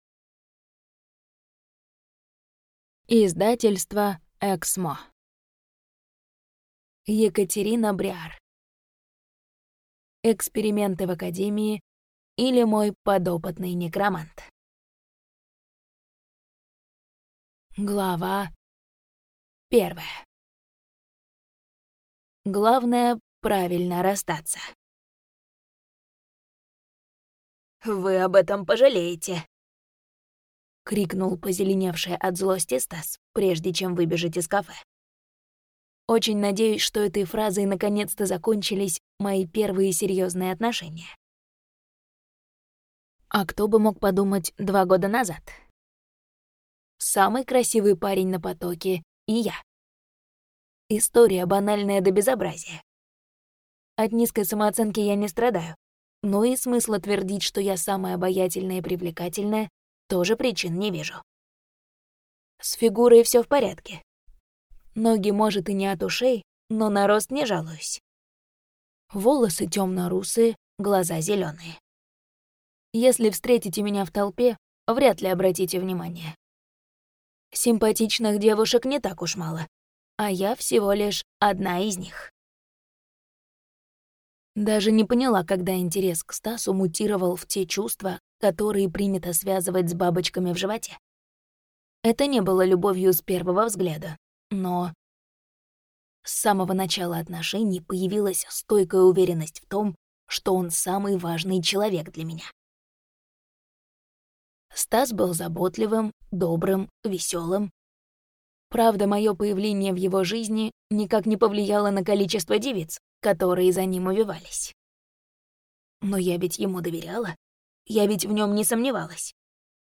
Аудиокнига Эксперименты в академии, или Мой подопытный некромант | Библиотека аудиокниг